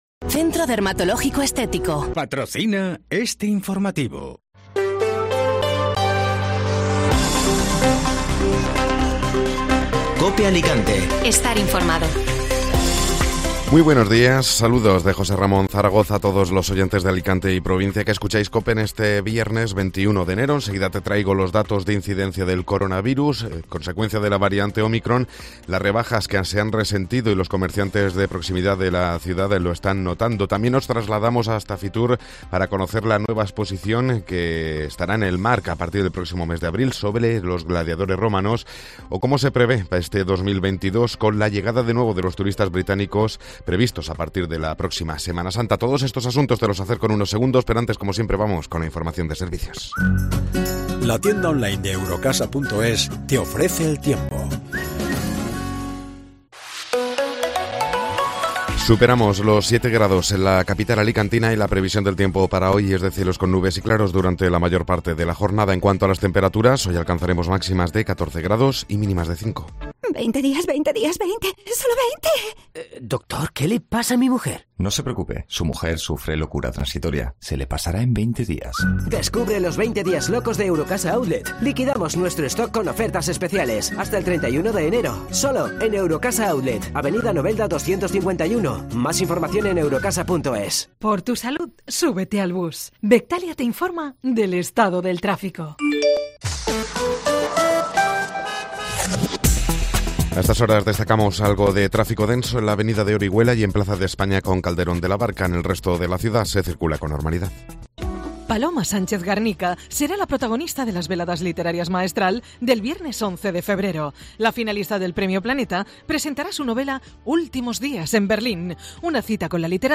Informativo Matinal (Viernes 21 de Enero)